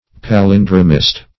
Palindromist \Pa*lin"dro*mist\, n. A writer of palindromes.